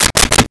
Switch 08
Switch 08.wav